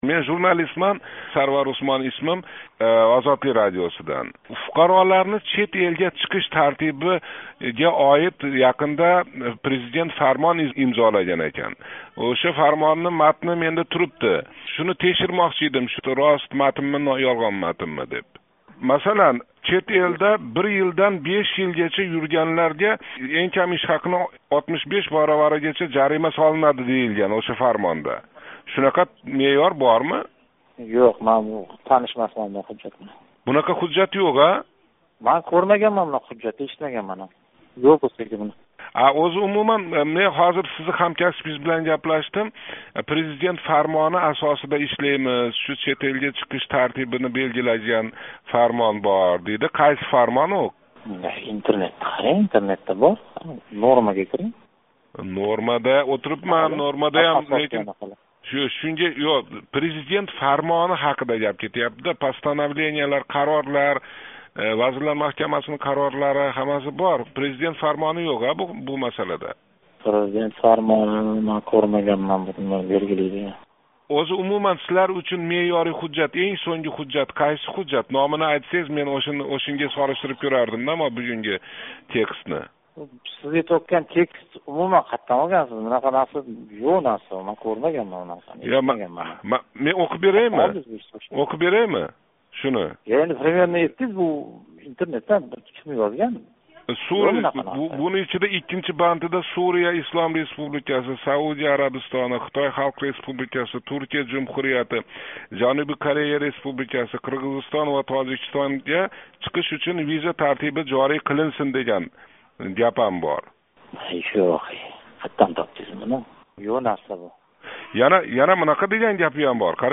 ОВИР расмийси жавоблари
ОВИР ходими билан суҳбат: